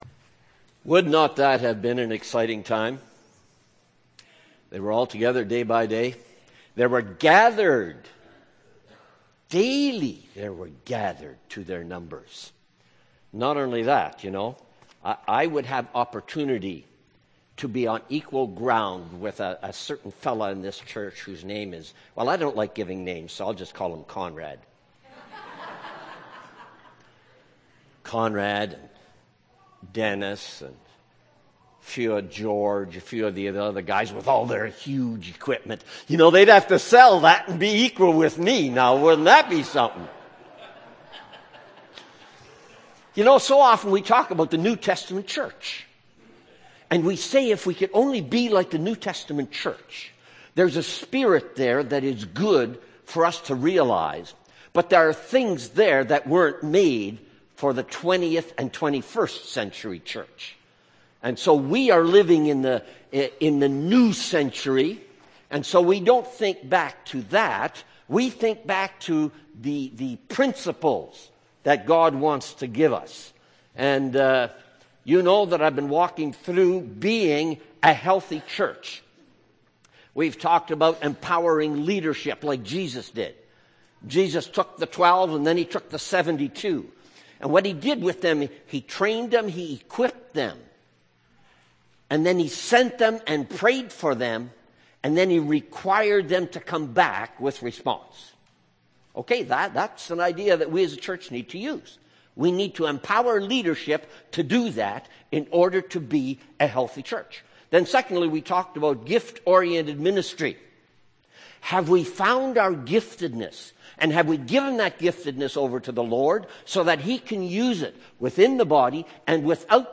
May 6, 2012 – Sermon